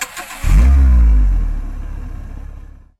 Car Engine Start
A car engine cranking and starting with ignition turn, starter motor, and idle settle
car-engine-start.mp3